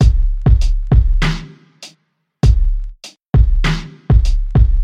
悲伤的博伊洛菲节拍
描述：阿德波伊洛菲的节拍，布鲁
Tag: 99 bpm Hip Hop Loops Drum Loops 835.37 KB wav Key : Unknown